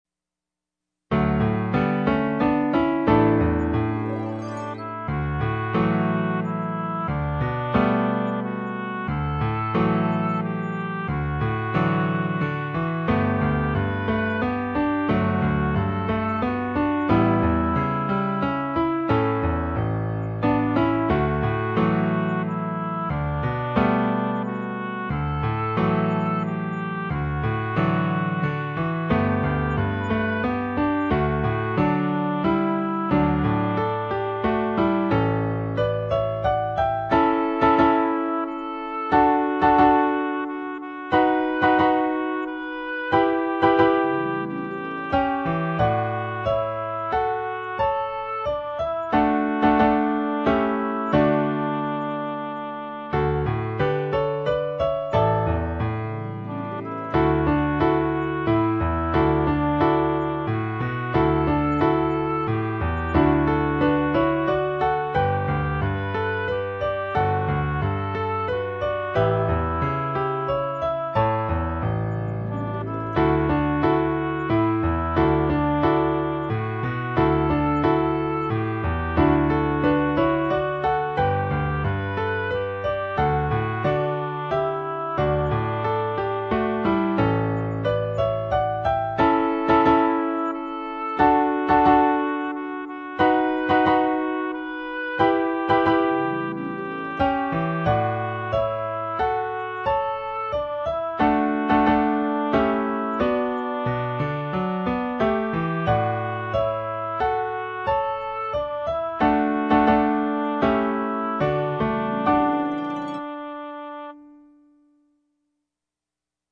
Voicing: Viola w/ Audio